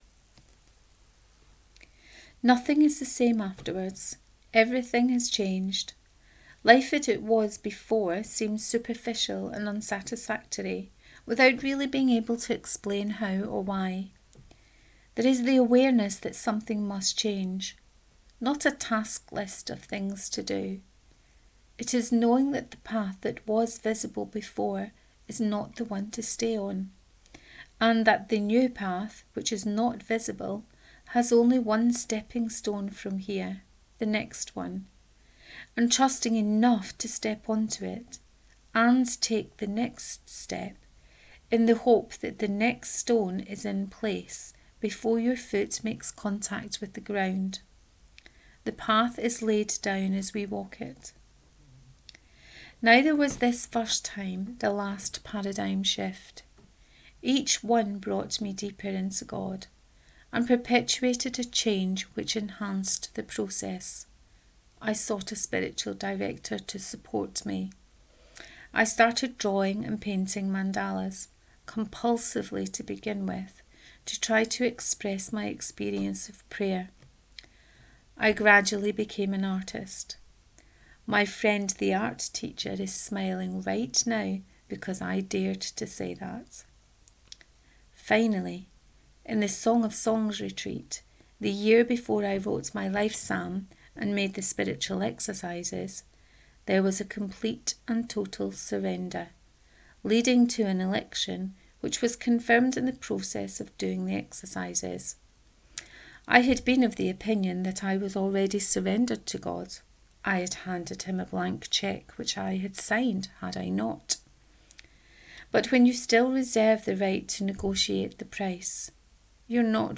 The Paradigm Shift 4 : Reading of this post